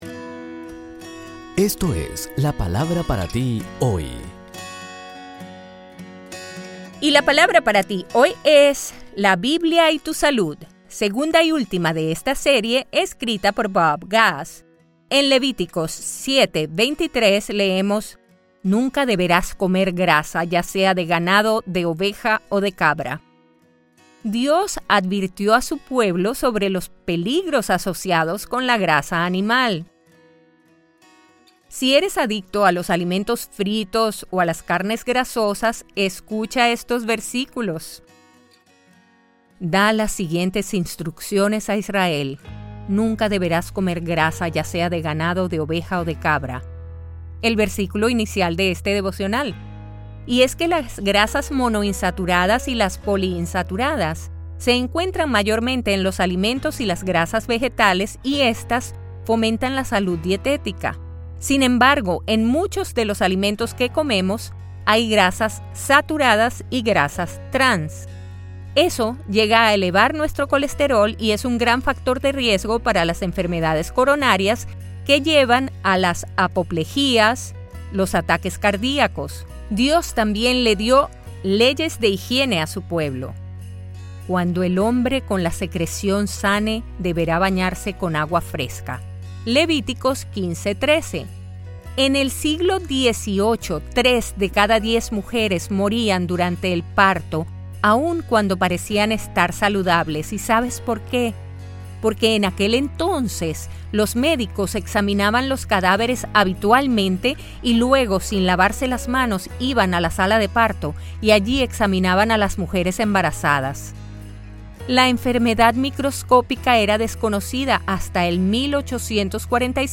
Escrito por Bob Gass y narrado por nuestra Elluz Peraza. Son devocionales llevados a podcast.